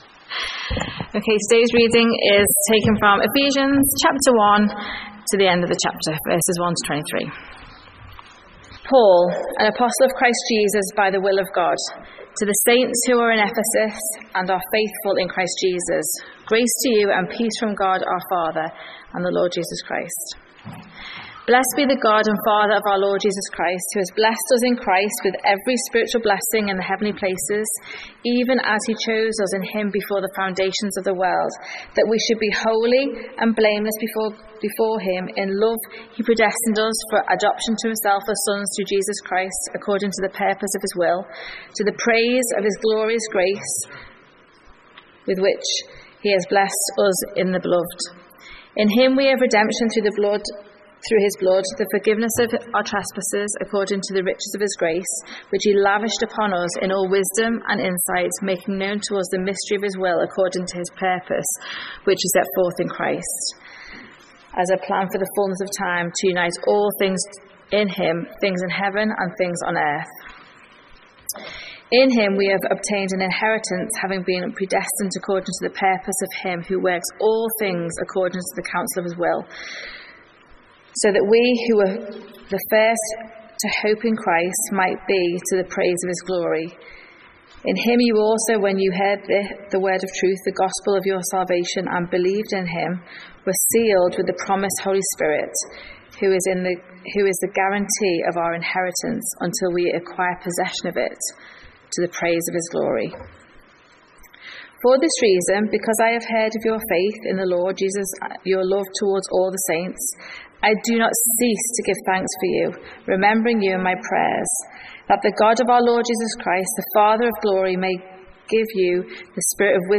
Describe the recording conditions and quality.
How can women be encouraged and motivated to share Christ's blessings with other women in their communities? From the 2025 Leaders' Conference.